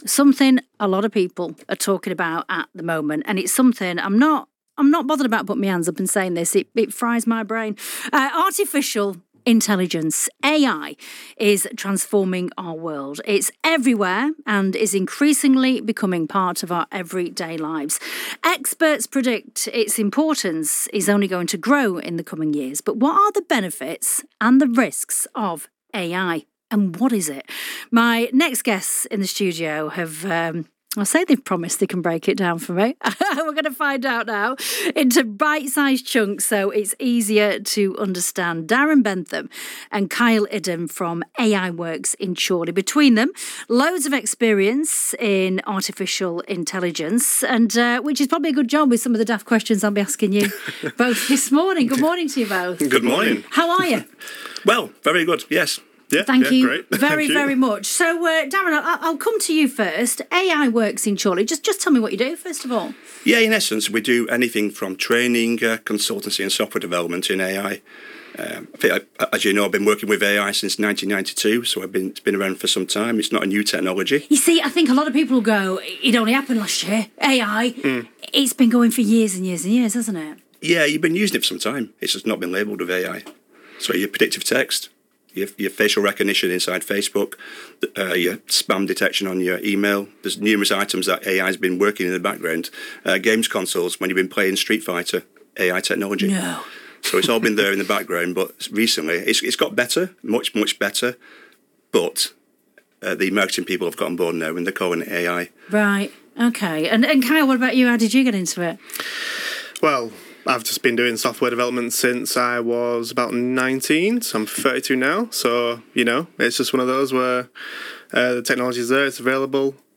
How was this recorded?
The AI Works in the media Our founders recently joined BBC Radio Lancashire to discuss how artificial intelligence is transforming businesses across the UK.